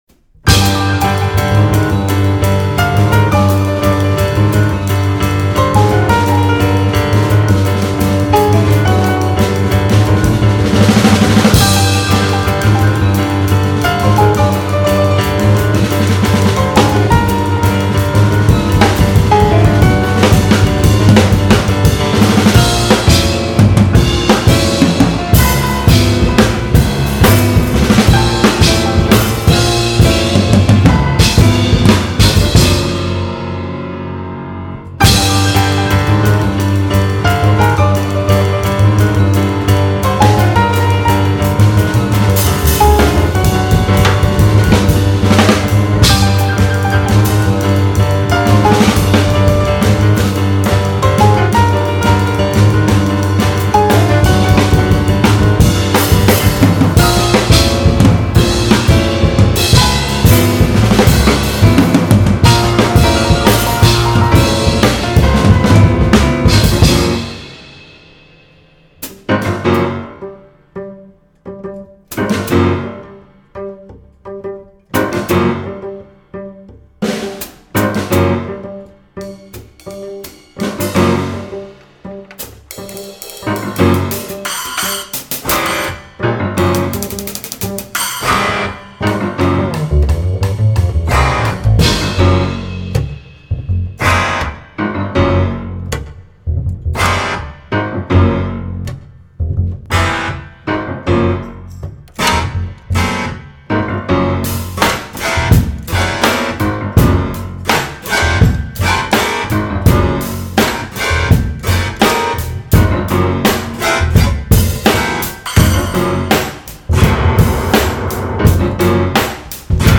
improvisent avec fougue, passion, amour, générosité
puisent dans le jazz, le rock, le funk, la musique orientale
Piano, fender rhodes
Batterie
Contrebasse, chant